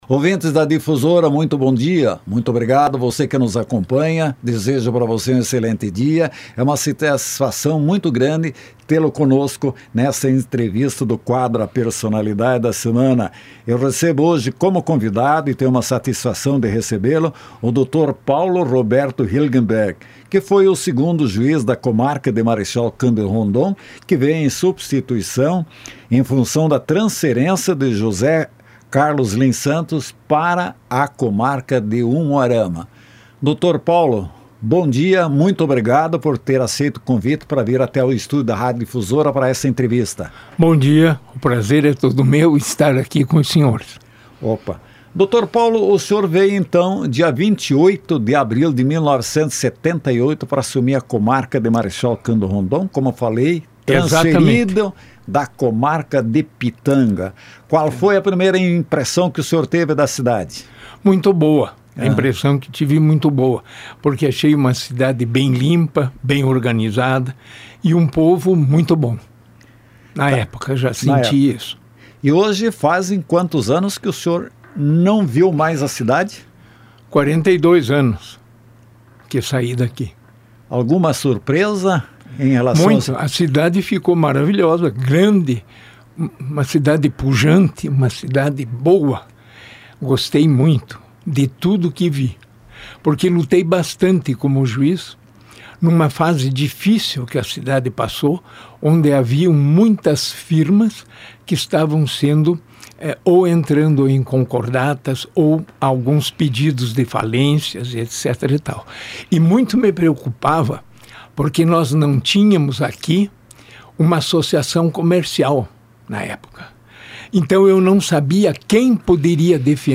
Doutor Paulo Roberto Hilgenberg foi o nosso entrevistado em A Personalidade da Semana